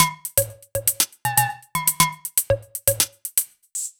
Index of /musicradar/french-house-chillout-samples/120bpm/Beats
FHC_BeatD_120-01_Tops.wav